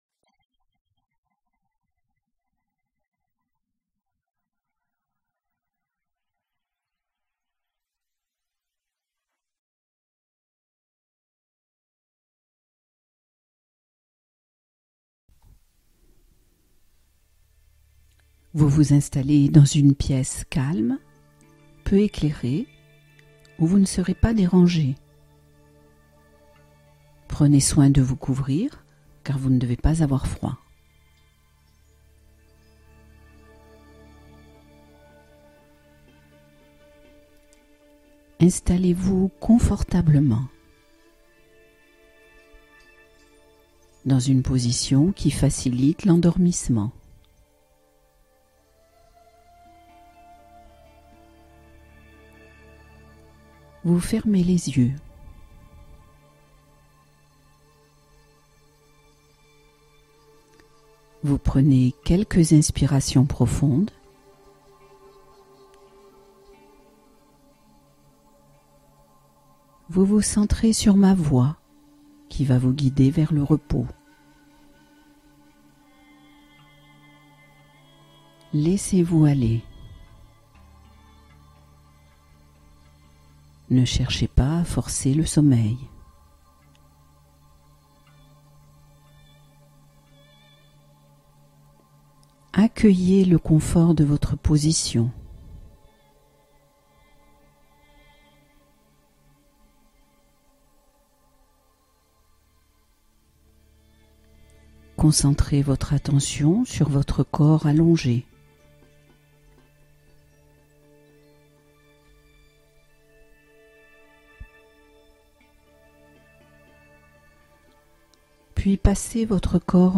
Relaxation guidée : retrouver un sommeil réparateur